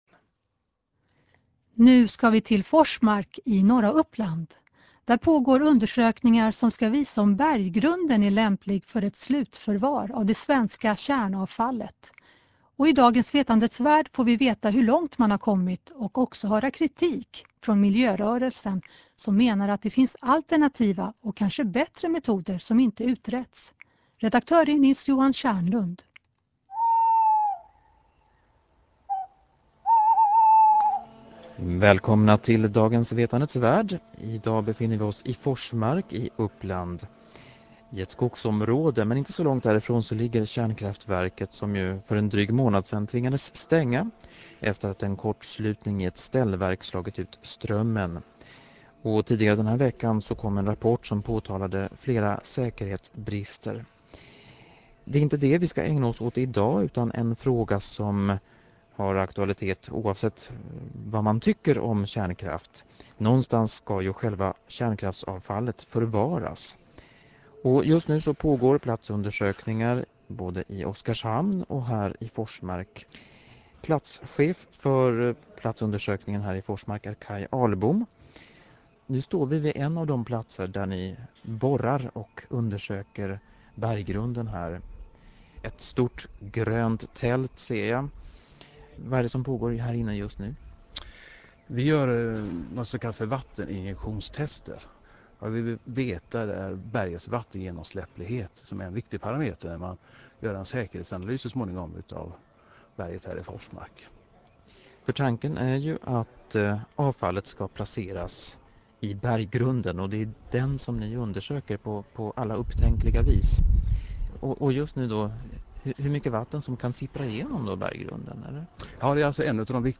Radioprogrammet "Vetandets värld" sände den 25/8 ett program om det svenska kärnavfallsprogrammet där bl a den alternativa metoden djupa borrhål diskuteras.